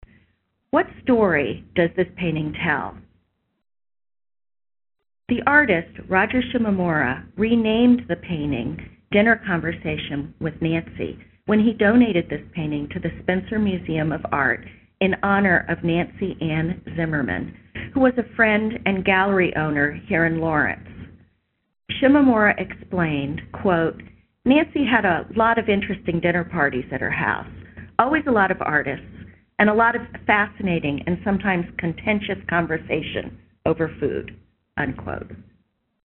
Ear for Art Audio Tour
Audio Tour – Ear for Art